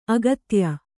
♪ agatya